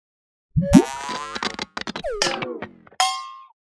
SA_remote_control.ogg